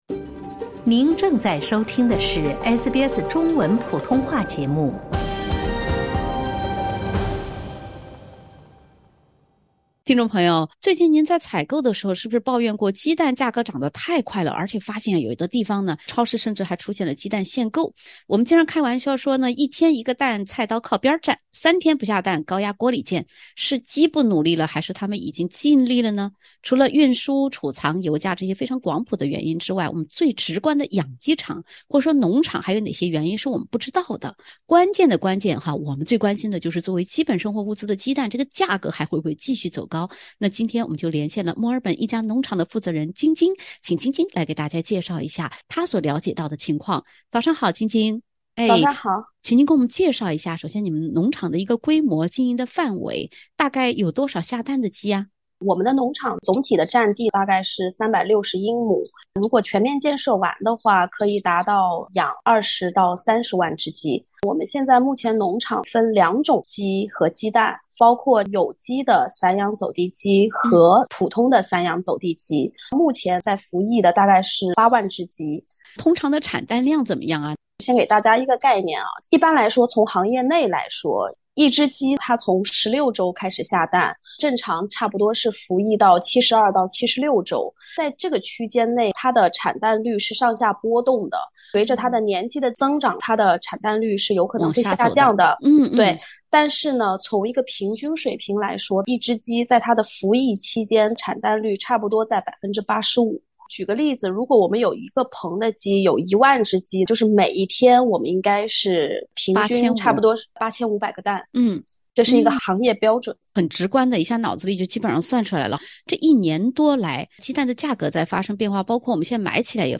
墨尔本一农场负责人表示，长远来看鸡蛋产量低价格高会持续。（点击封面图片，收听完整采访）